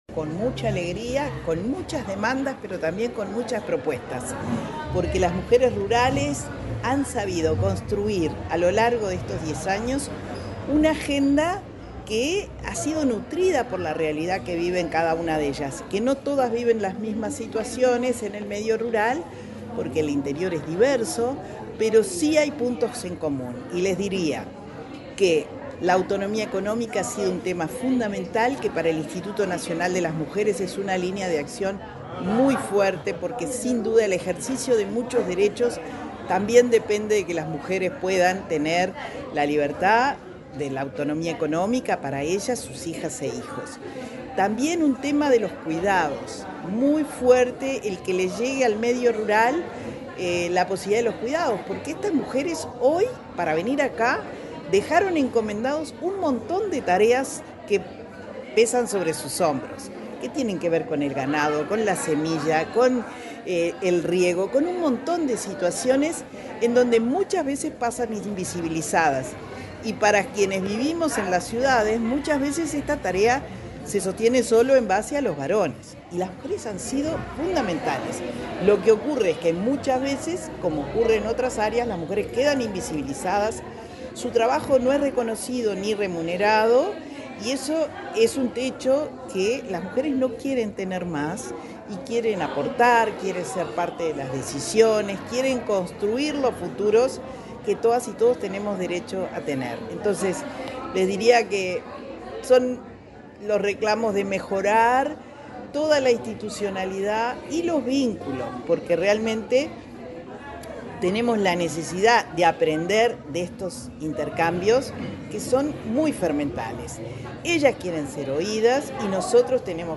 Declaraciones de la directora de Inmujeres, Mónica Xavier
La directora del Instituto Nacional de las Mujeres (Inmujeres), Mónica Xavier, dialogó con la prensa, luego de participar en el acto de cierre del